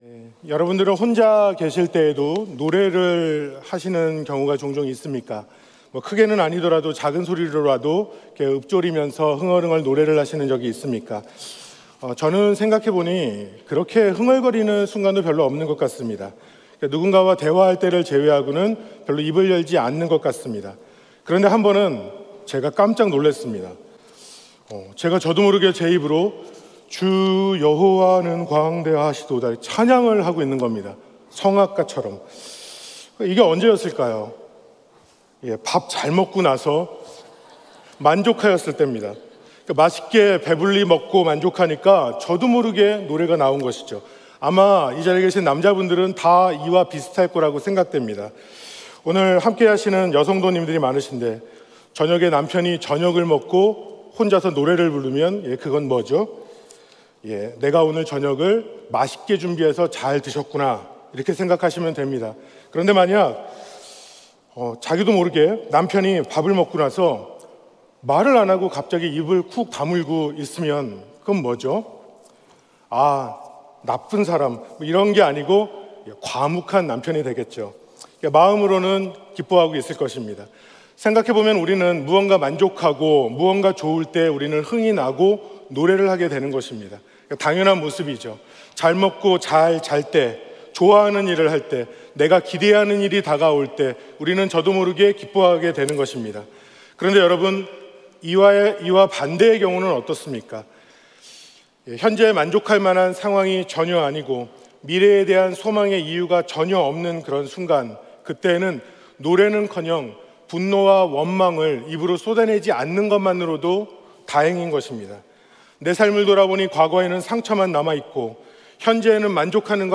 2024년 고난주간 특별새벽기도회 다섯째날 | 십자가로 회복 – 찬양 (3/29/2024)
예배: 특별 집회